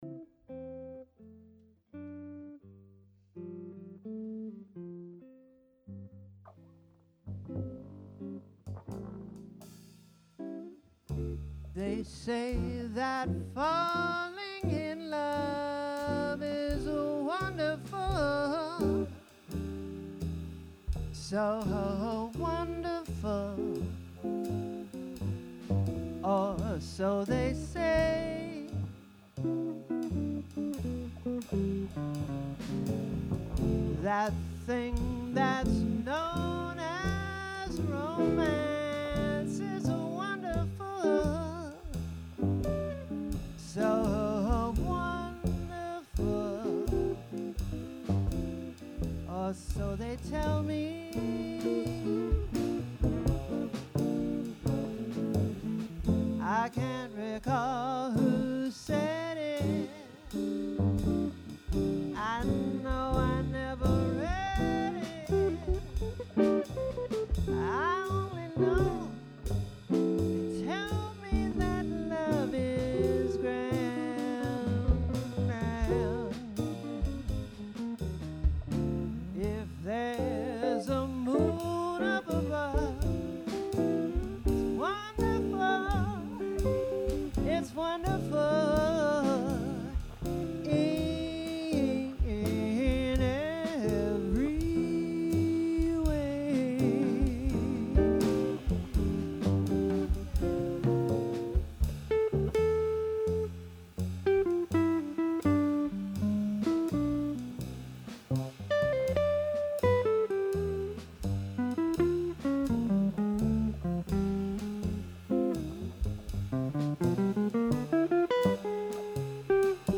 Sax and Vocals
Drums
Bass
Guitar